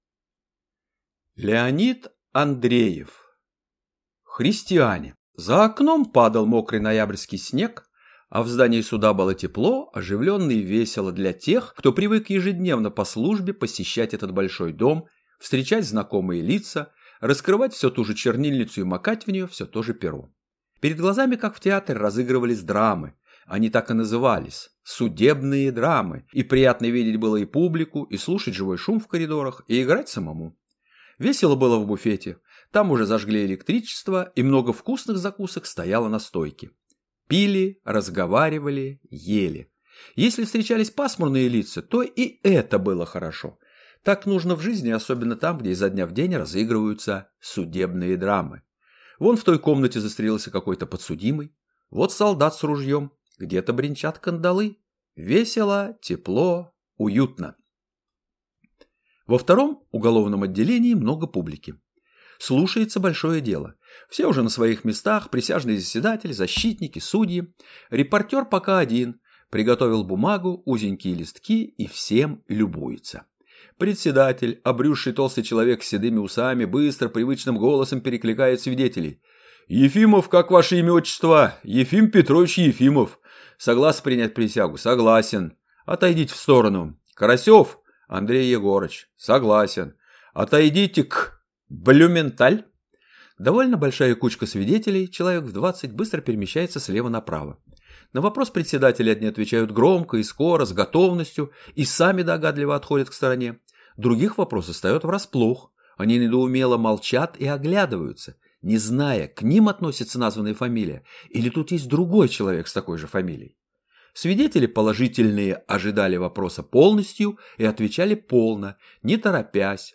Аудиокнига Христиане | Библиотека аудиокниг